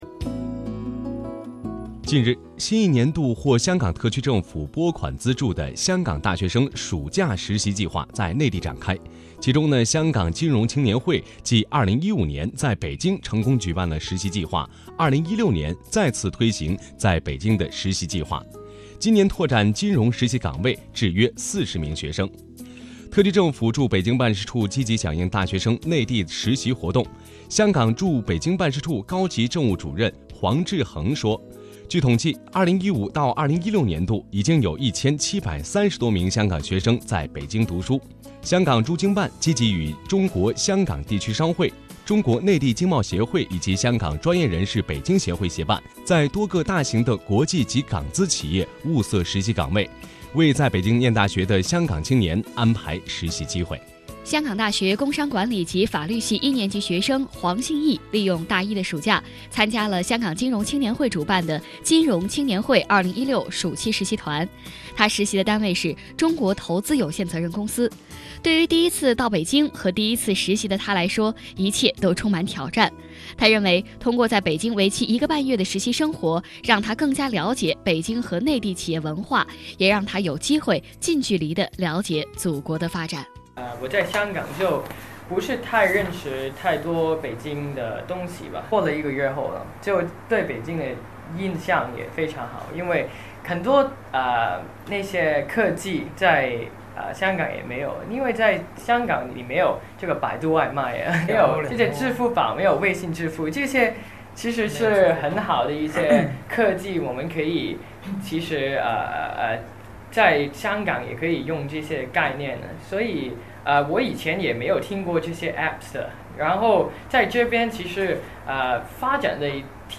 随着两地交流日益扩大，驻京办加强了在华北地区的宣传和推广工作，并自2006年起与中央人民广播电台「华夏之声」（2019年9月起更名为中央广播电视总台大湾区之声）携手打造普通话广播节目「每周听香港」，在华北九个省、市、自治区级电台播出，以趣味与信息并重的形式，把香港的最新发展带给当地听众。